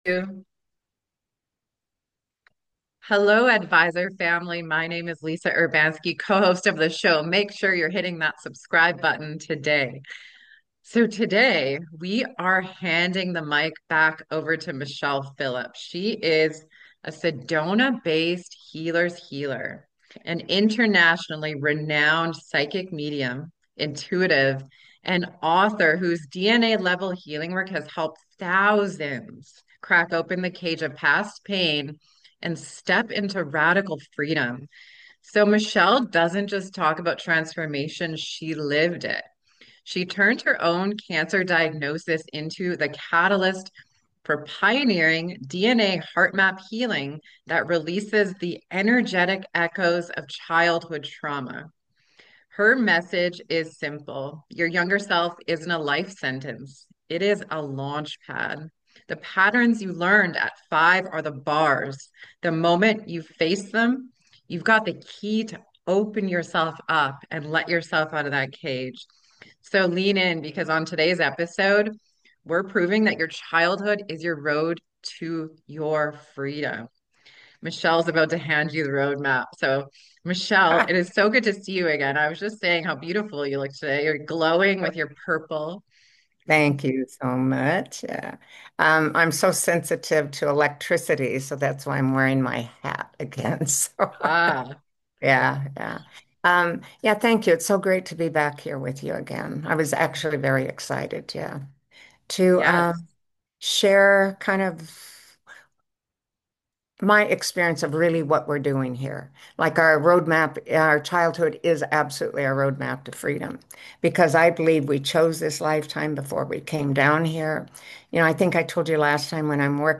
Whether you’re curious about past‑life regression or seeking deep relationship healing, this conversation offers a fast track to freedom and a fresh love story.